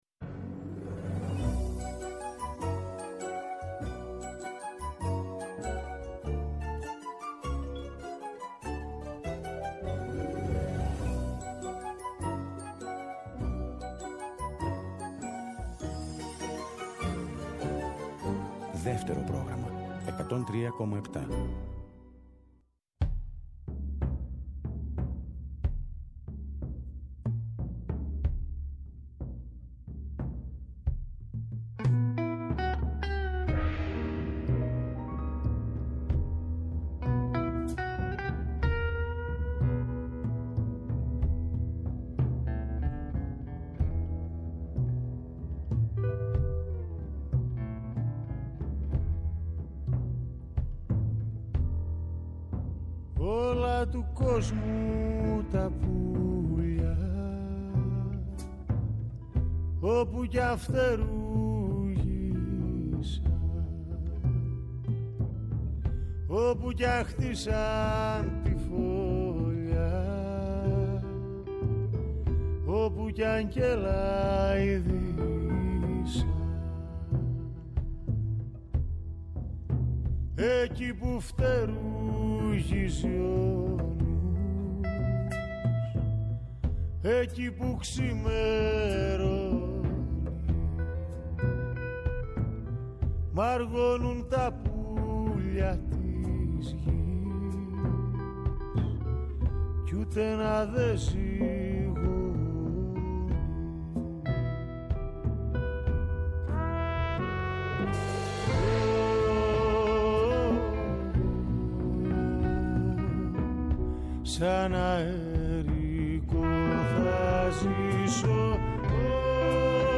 βιολί
κλασική κιθάρα
κοντραμπάσο
Live στο Studio